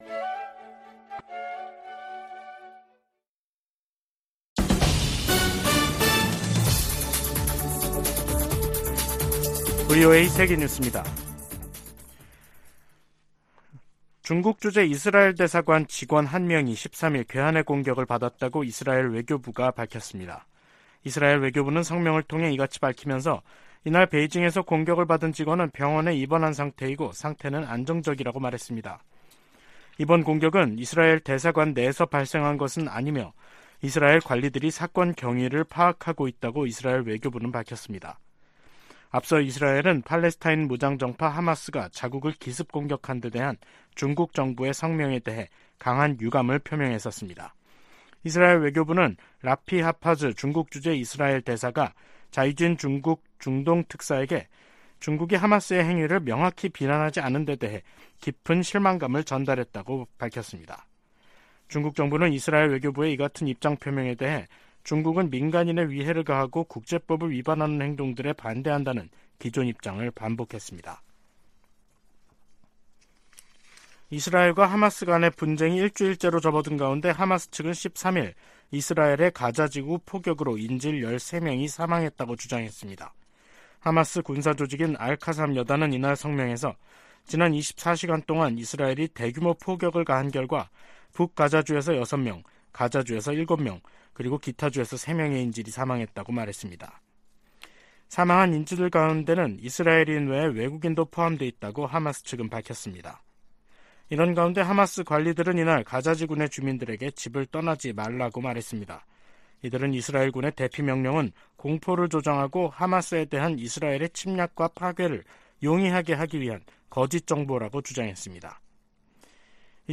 VOA 한국어 간판 뉴스 프로그램 '뉴스 투데이', 2023년 10월 13일 3부 방송입니다. 이스라엘과 하마스의 전쟁 등 중동발 위기가 한반도에 대한 미국의 안보 보장에 영향을 주지 않을 것이라고 백악관이 밝혔습니다. 북한이 하마스처럼 한국을 겨냥해 기습공격을 감행하면 한미연합사령부가 즉각 전면 반격에 나설 것이라고 미국 전문가들이 전망했습니다. 중국 내 많은 북한 주민이 송환된 것으로 보인다고 한국 정부가 밝혔습니다.